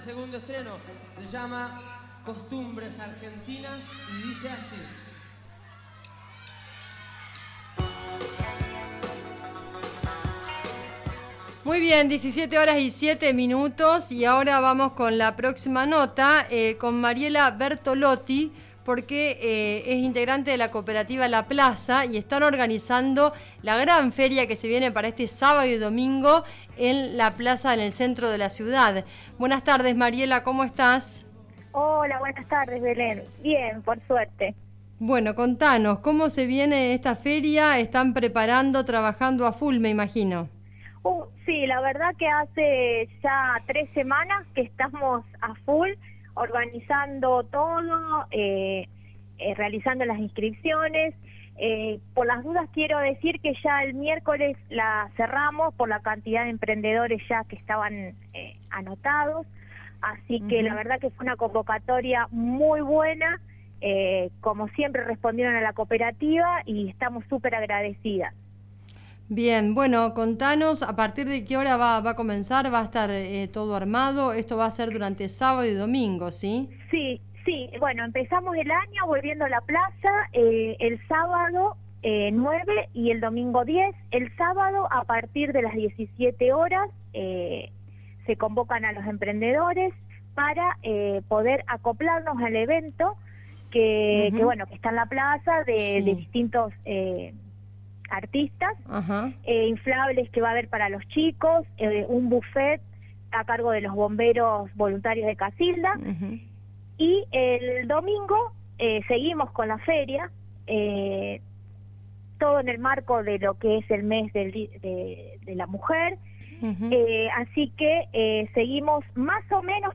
En contacto con el programa Zona Sur, de Radio Del Sur FM 90.5